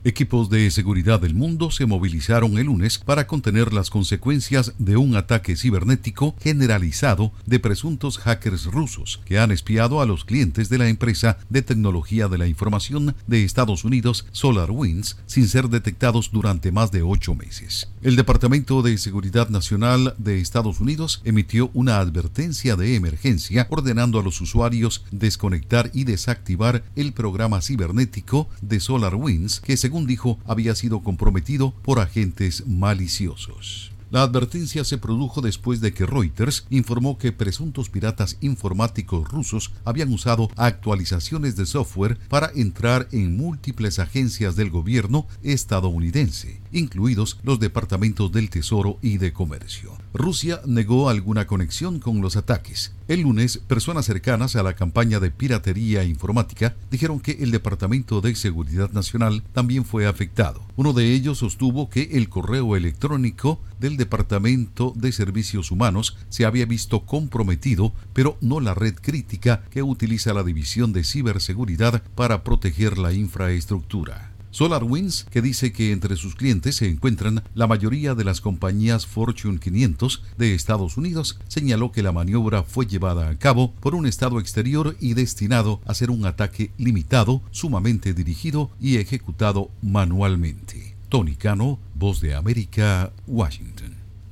Equipos de seguridad del mundo evalúan el impacto del ciberataque presuntamente ruso. Informa desde la Voz de América en Washington